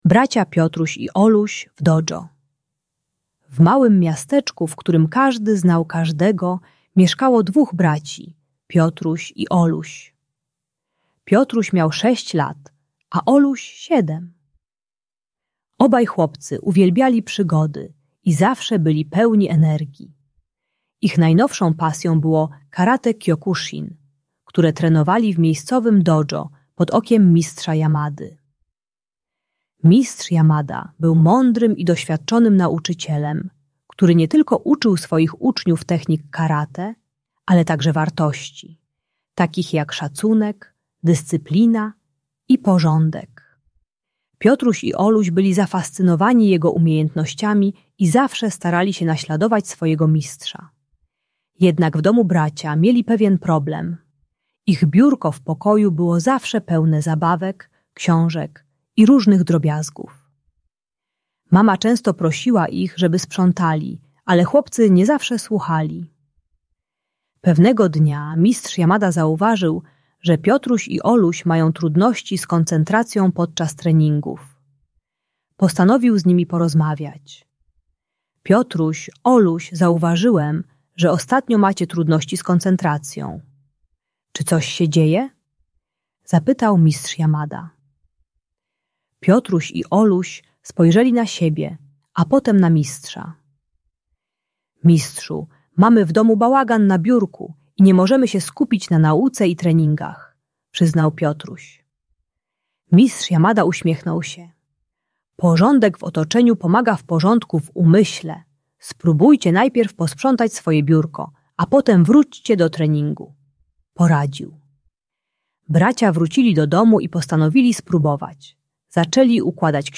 Audiobajka uczy techniki codziennego mini-sprzątania przez kilka minut, które buduje nawyk porządku i poprawia skupienie. Historia o dyscyplinie i odpowiedzialności.